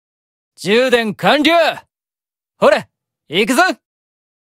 File:Mammon AP Notification (NB) Voice.ogg
Mammon_AP_Notification_(NB)_Voice.ogg.mp3